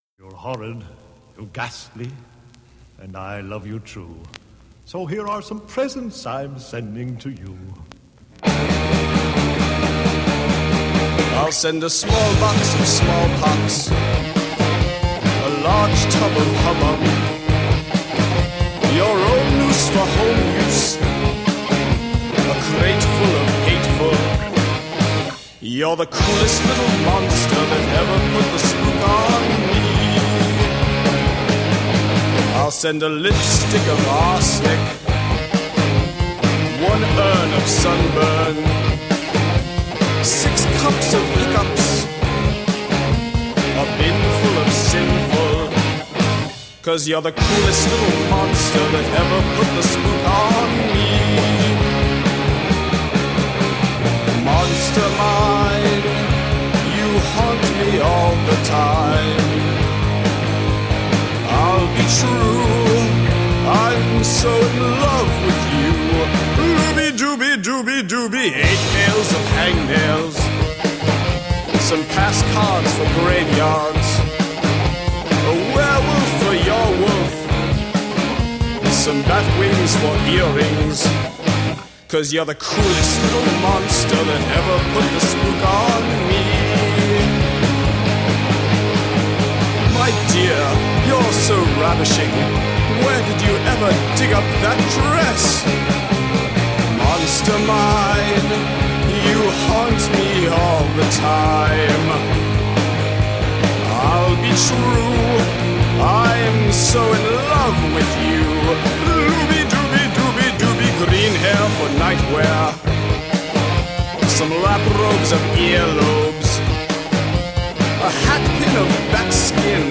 High energy punk rock & roll.
Tagged as: Hard Rock, Punk, Rock, High Energy Rock and Roll